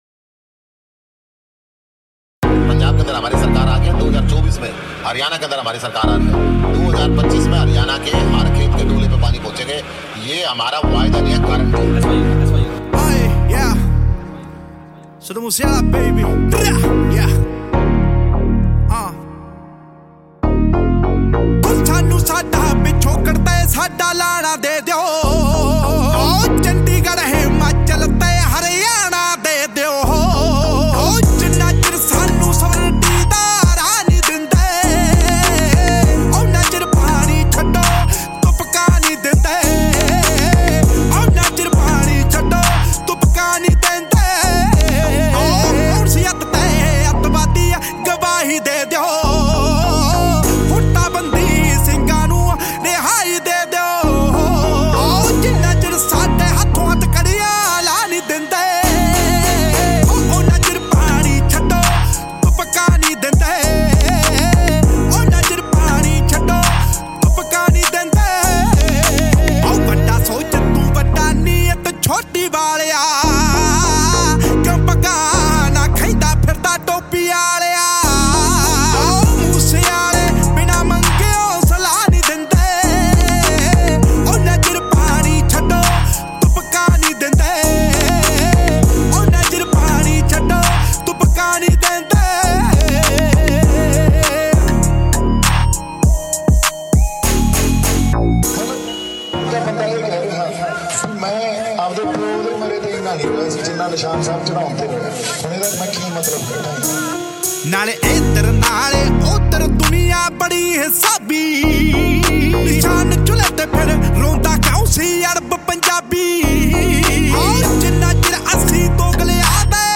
is a old Punjabi song from the 2022 album